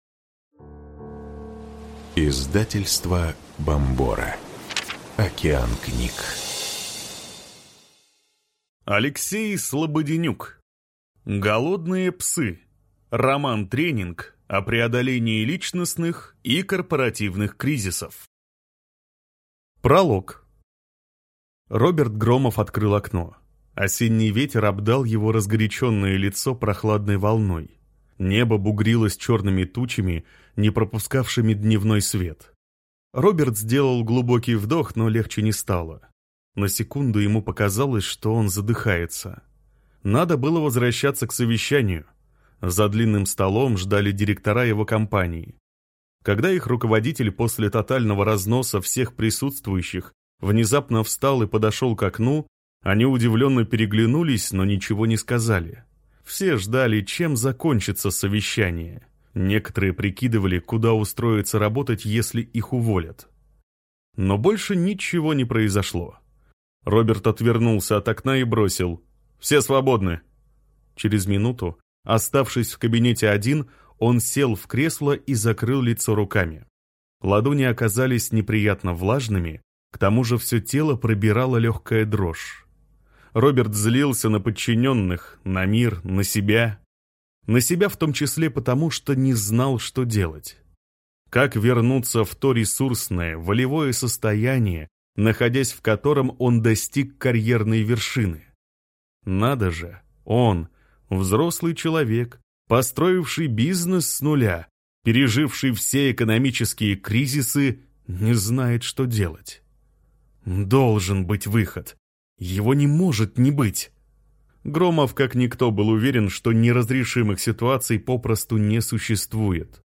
Аудиокнига Голодные псы. Роман-тренинг о преодолении личностных и корпоративных кризисов | Библиотека аудиокниг